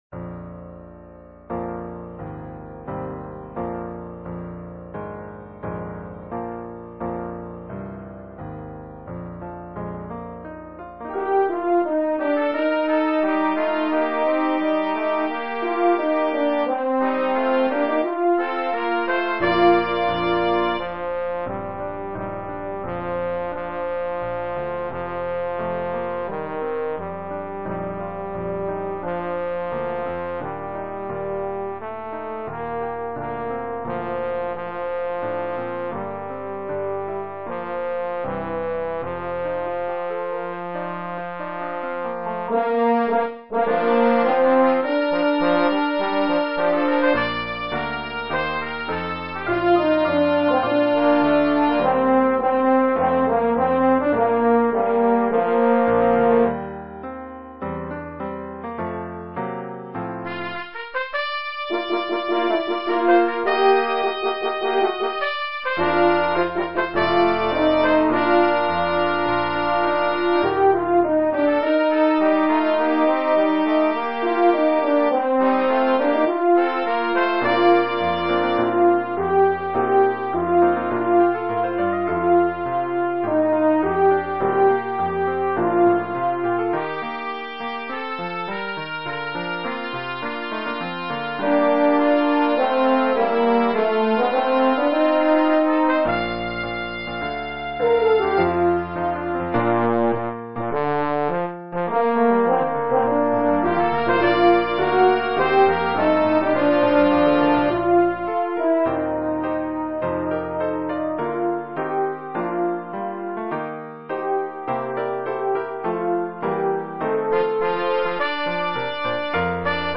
Bb, G
Brass trio
Song (ternary)